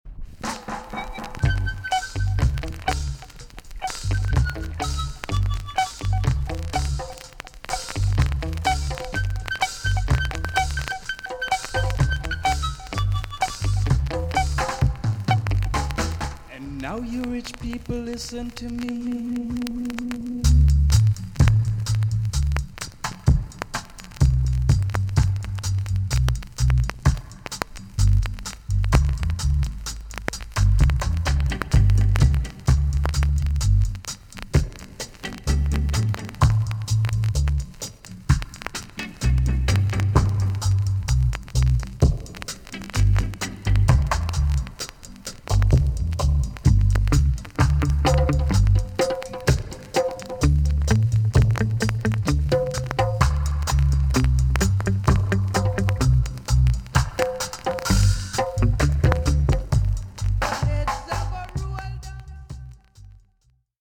TOP >REGGAE & ROOTS
B.SIDE Version
VG+ 軽いチリノイズがあります。